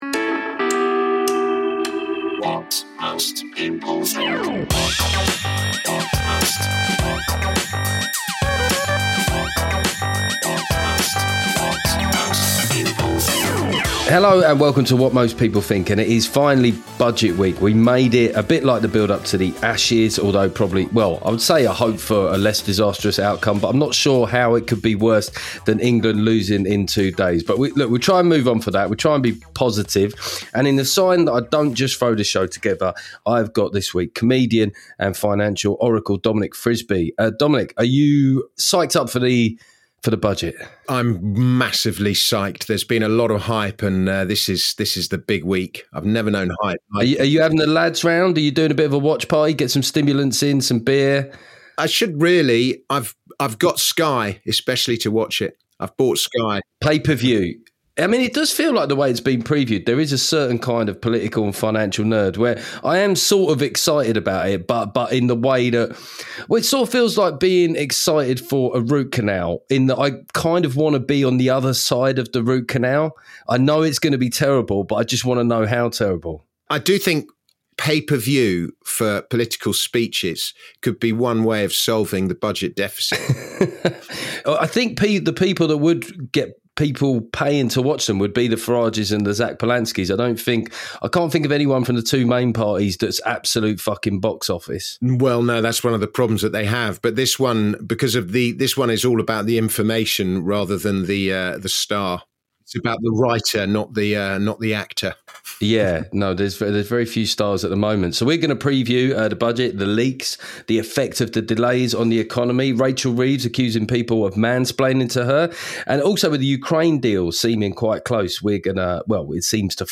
In the week of the budget there was no better guest than comedian and financial oracle Dominic Frisby. We make predictions, but also consider bizarre forfeits for leaking to the press.